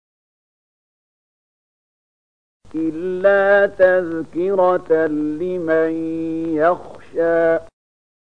020003 Surat Thaahaa ayat 3 dengan bacaan murattal ayat oleh Syaikh Mahmud Khalilil Hushariy: